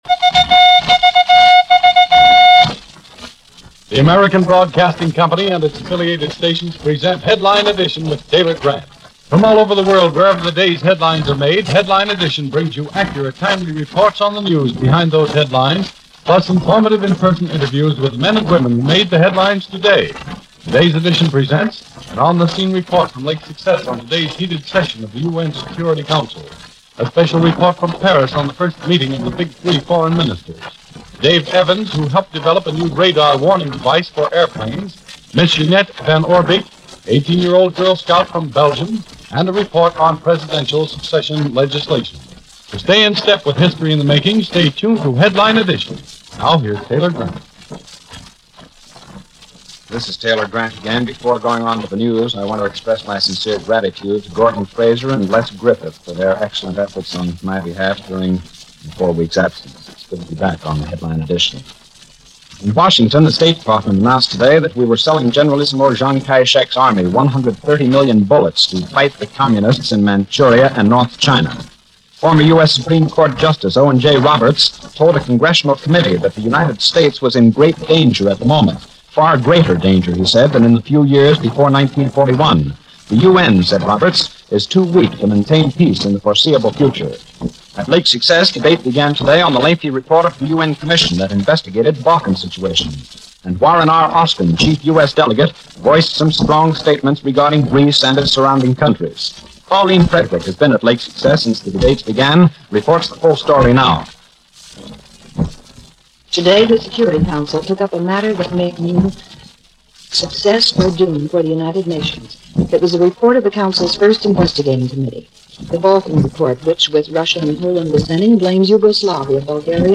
News of this day in history from ABC Radio's Headline Edition June 27, 1947